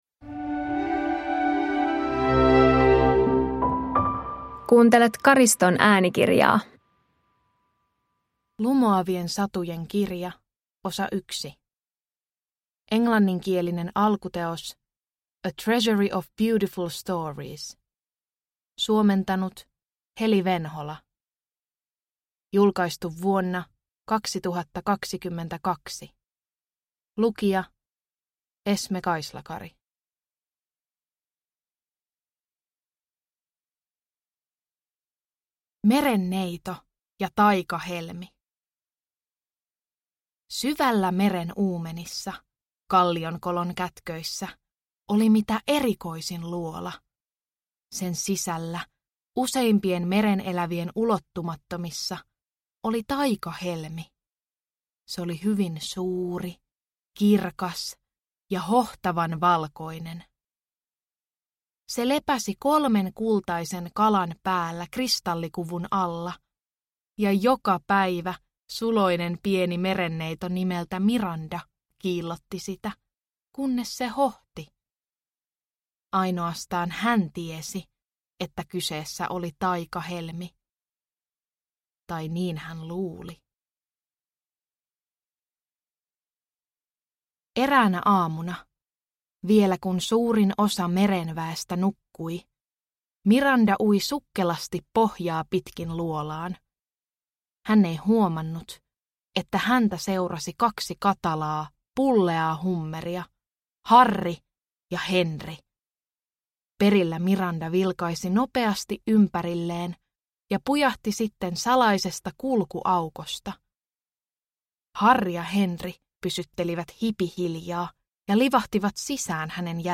Lumoavien satujen kirja 1 – Ljudbok – Laddas ner